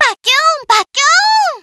pause-retry-click.wav